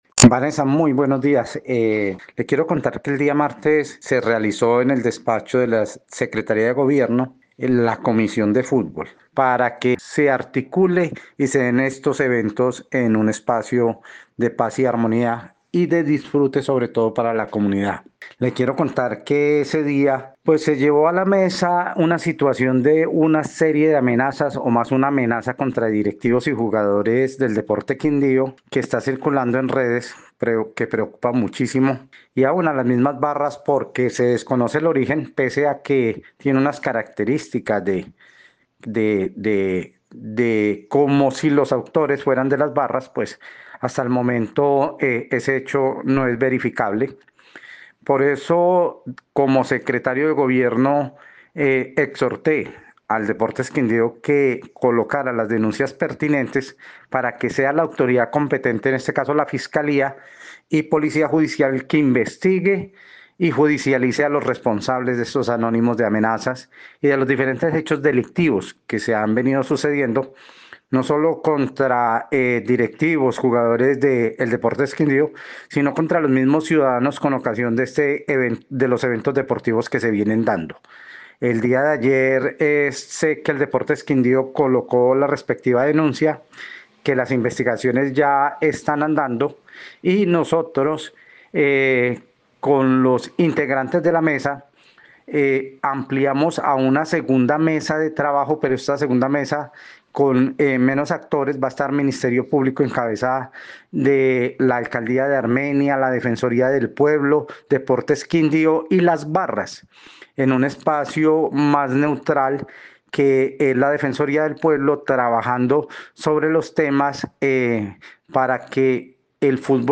Secretario de Gobierno de Armenia